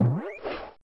trampBounce_1.wav